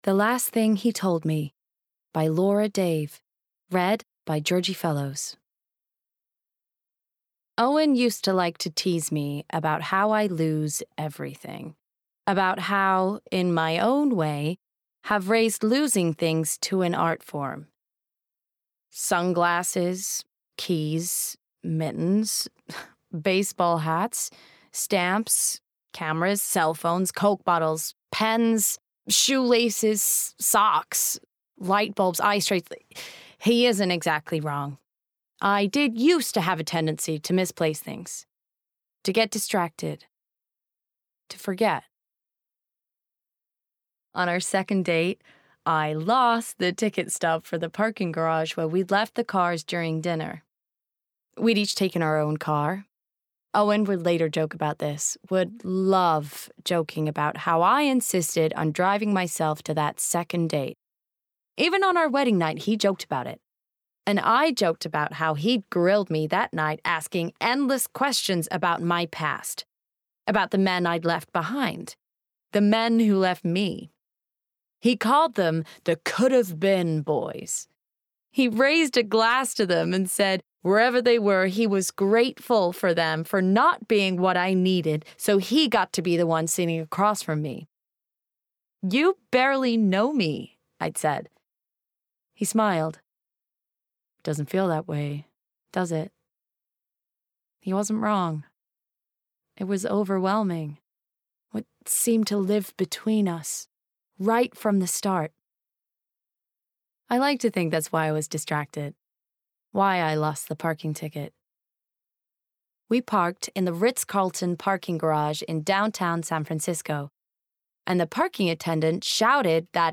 20/30's Neutral/West Country,
Modern/Fresh/Engaging